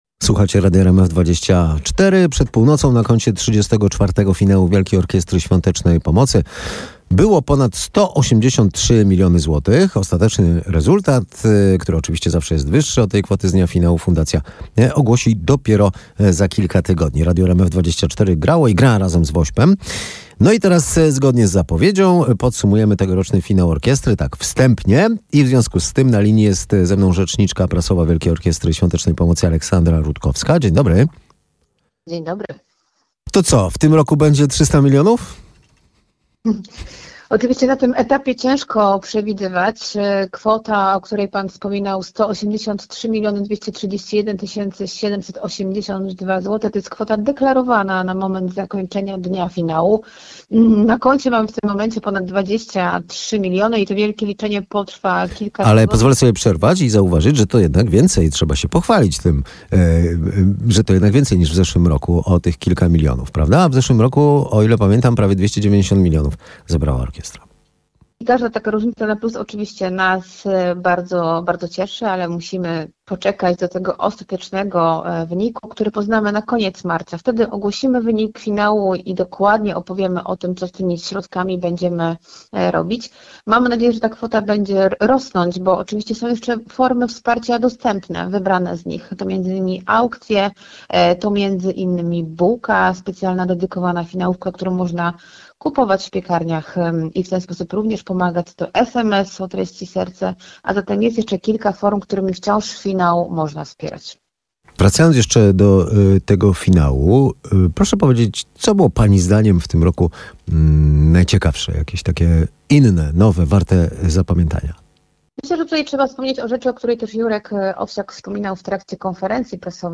Odsłuchaj starsze transmisje RMF FM!
18:00 Fakty i Popołudniowa rozmowa w RMF FM - 26.01.2026